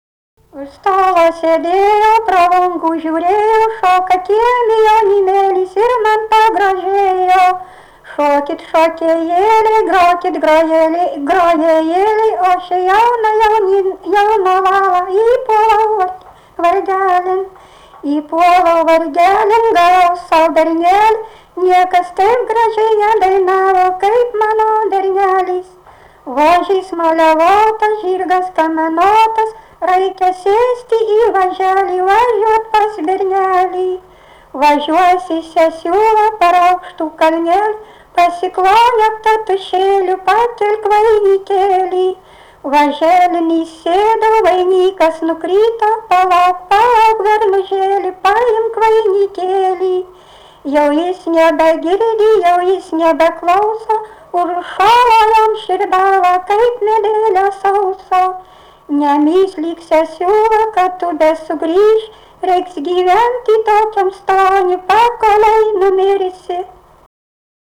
Dalykas, tema daina
Erdvinė aprėptis Suvainiai
Atlikimo pubūdis vokalinis